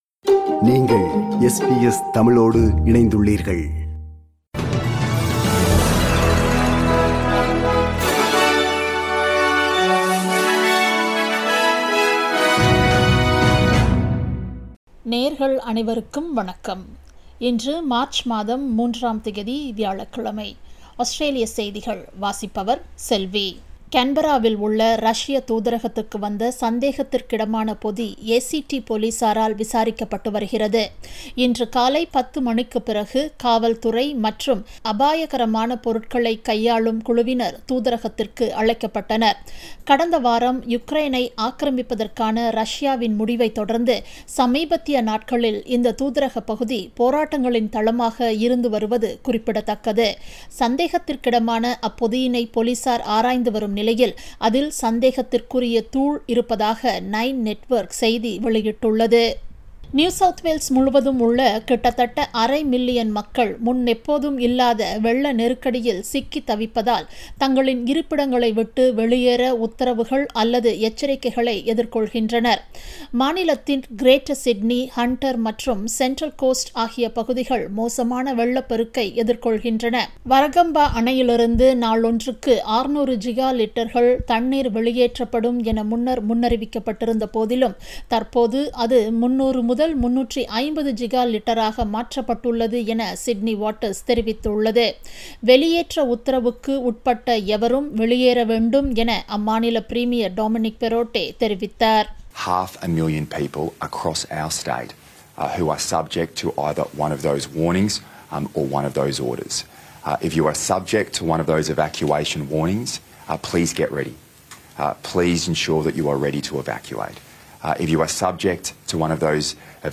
Australian news bulletin for Thursday 03 March 2022.